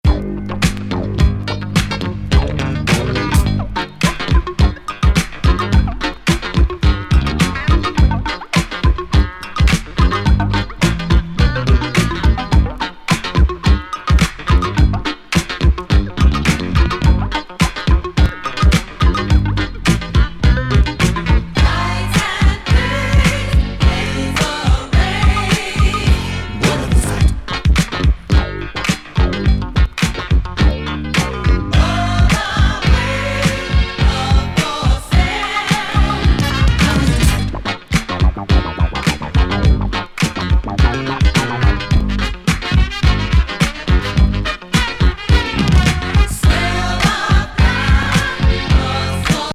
アタッキン・ディスコ!